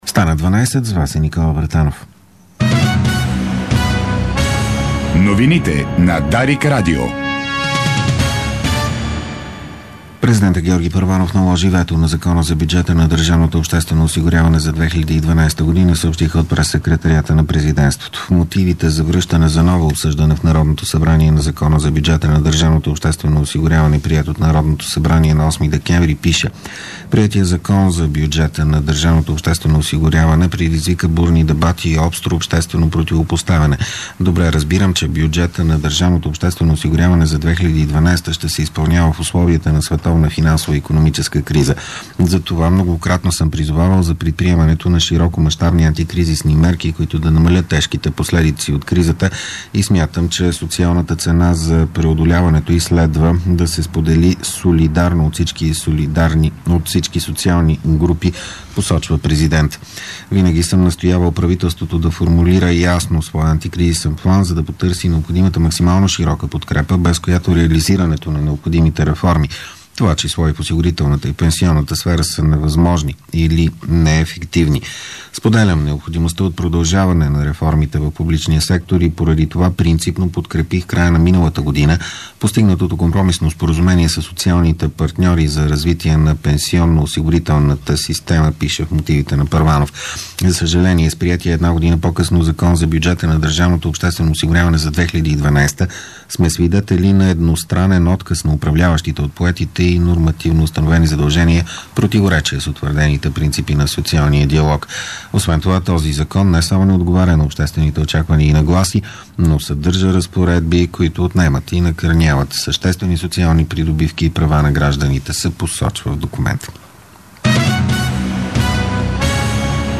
Обедна информационна емисия - 11.12.2011